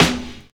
• Snare B Key 115.wav
Royality free snare sound tuned to the B note.
snare-b-key-115-UAI.wav